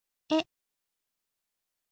ออกเสียง: e, เอะ